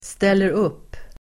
Uttal: [steler'up:]